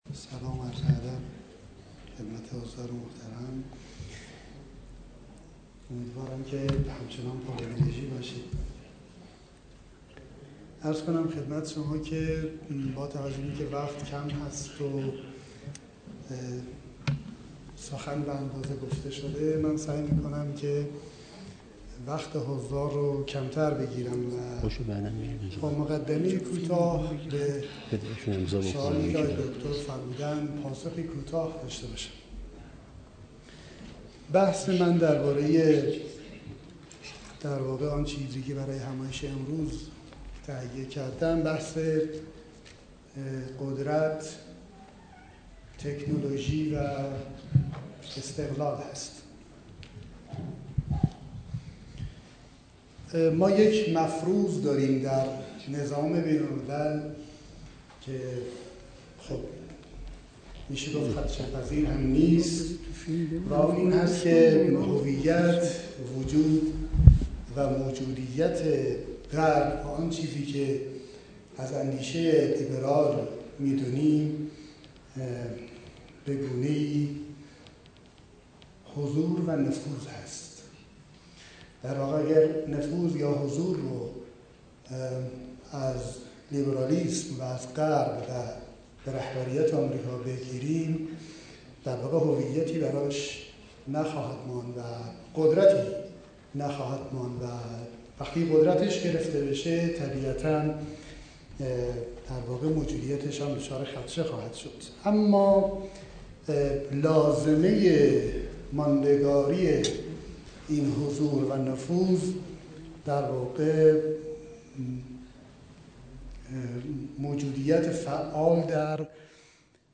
در همایش ملی «سلطه و نفود» گفت: جنگ امروز، جنگ بدون گلوله است. در جنگ‌های بزرگ تمدنی عموما گلوله‌ای رد و بدل نمی‌شود، اندیشه رد و بدل می‌شود